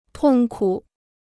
痛苦 (tòng kǔ): pain; suffering.